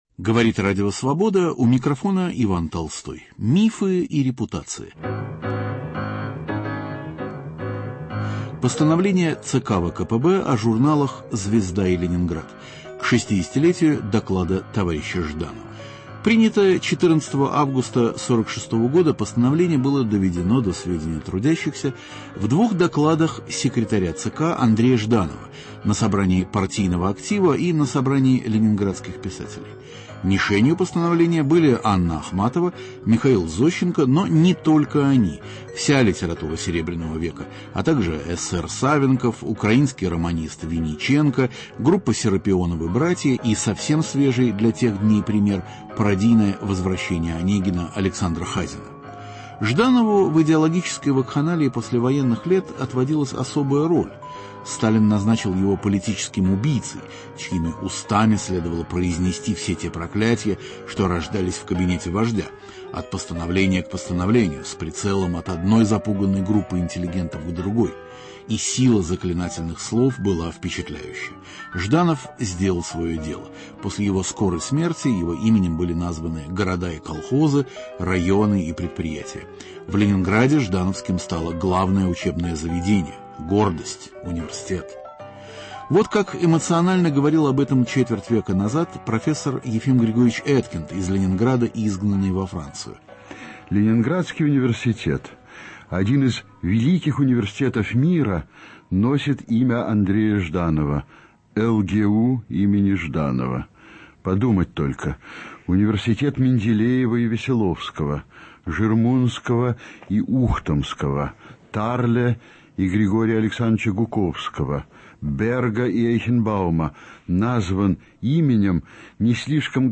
Ведущий Иван Толстой поднимает острые, часто болезненные, вопросы русского культурного процесса: верны ли устоявшиеся стереотипы, справедливы ли оценки, заслуженно ли вознесены и несправедливо ли забыты те или иные деятели культуры?